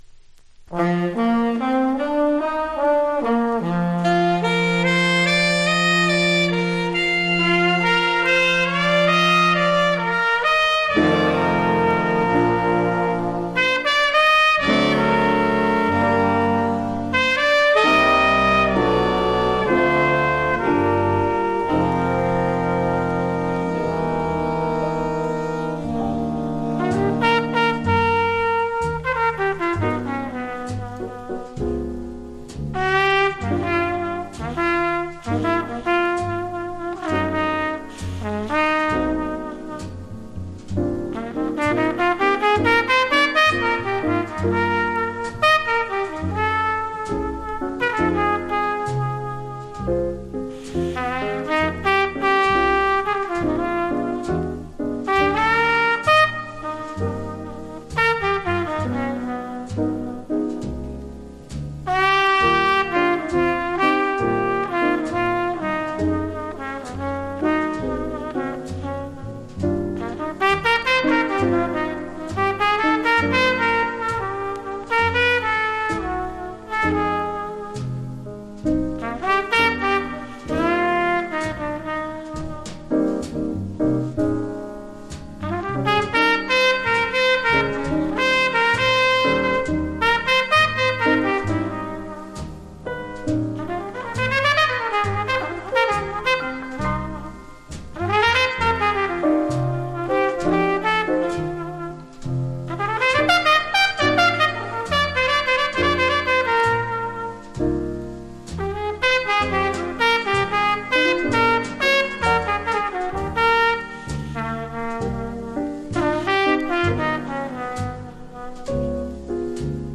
（MONO針で聴くとほとんどノイズでません）
Genre US JAZZ